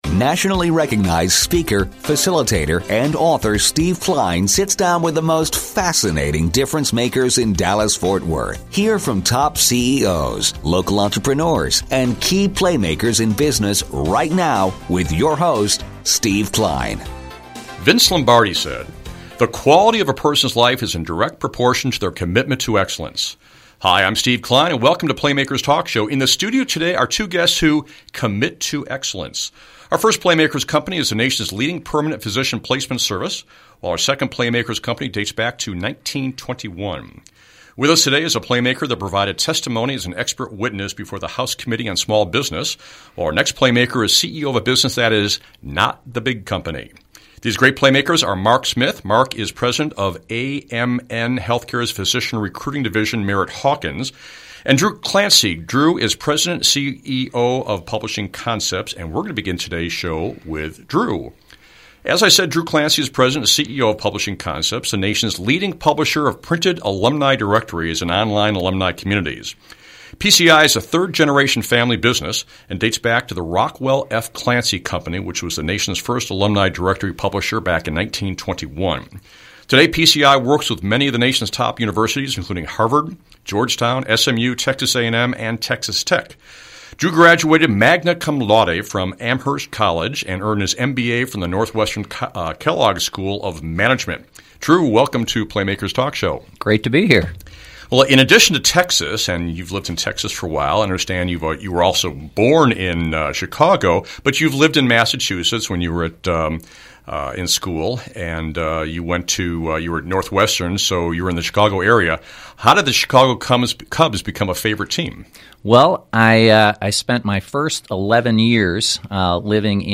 On the September 12th edition of PlayMakers Talk Show, our guests were: